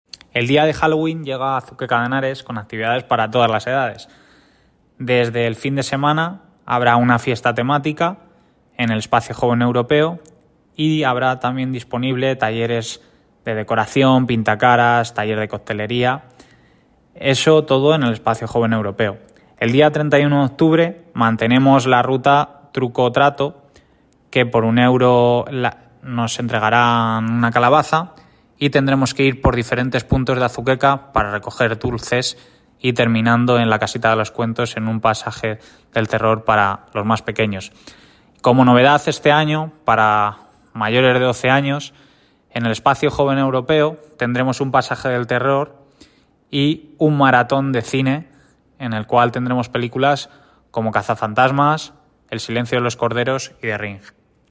Declaraciones del concejal Enrique Pérez de la Cruz